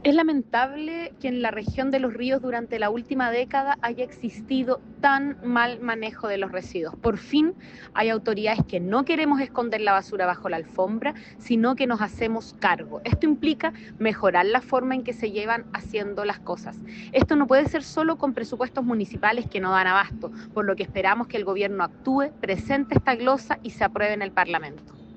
La alcaldesa de Valdivia y presidenta de la Asociación de Municipalidades de Los Ríos, Carla Amtmann, apuntó a un mal manejo de la basura en las administraciones anteriores, afirmando que los municipios “no dan abasto” para enfrentar el alza.